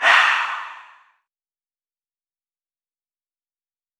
Vox (Breath).wav